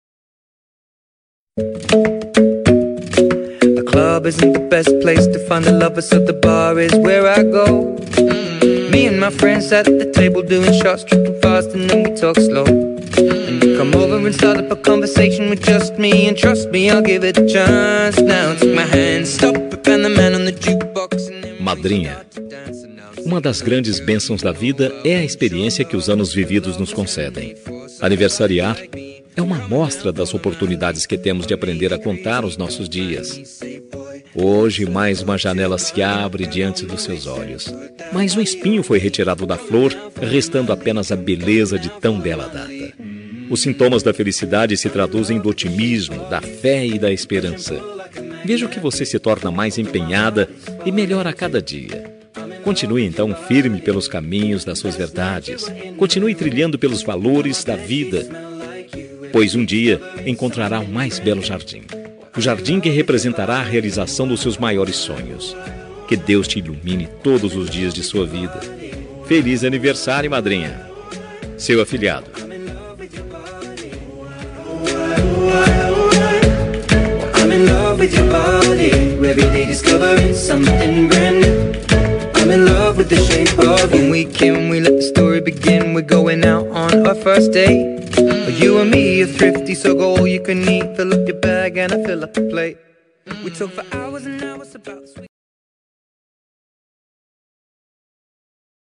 Aniversário Madrinha – Voz Masculina – Cód: 04218